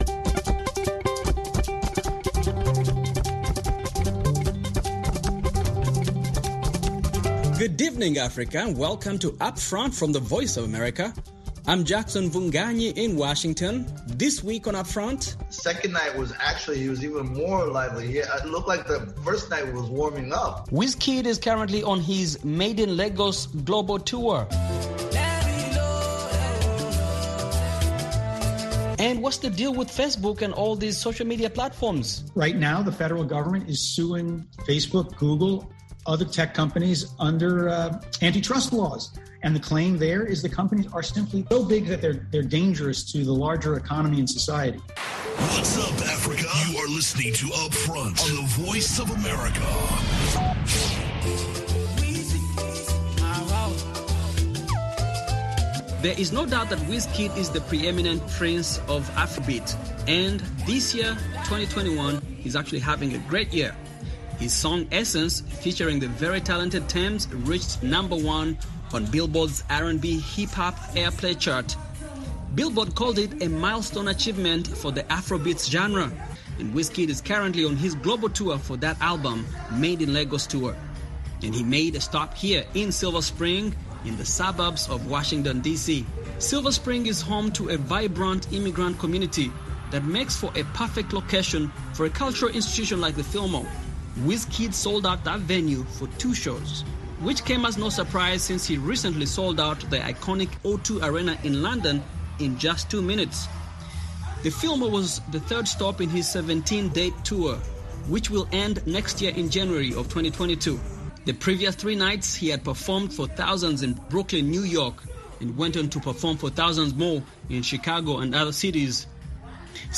talk to teens and young adults about Politics,trends, lifestyles, health, entertainment, and other issues touching listeners’ lives